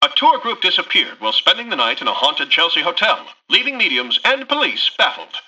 Newscaster_headline_28.mp3